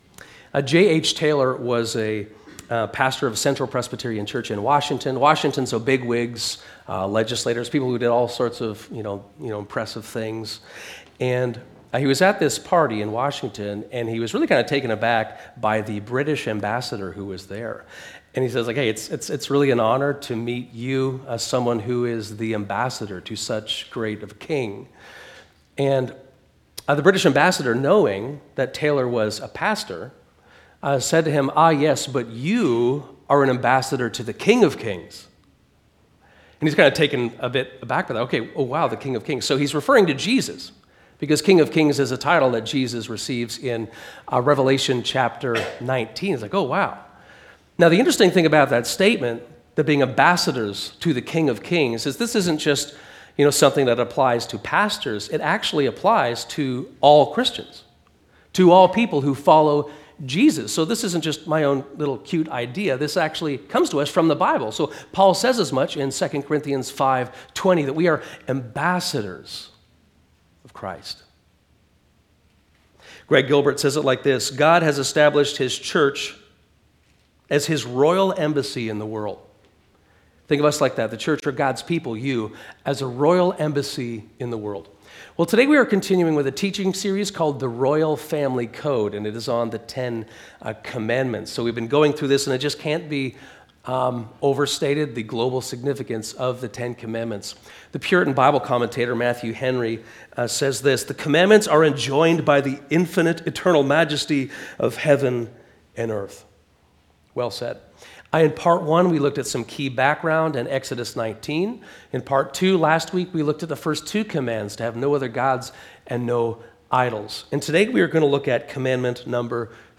This sermon is a part of our series on the 10 Commandments called The Royal Family Code. It explores Commandment number 3, to not take God’s name in vain.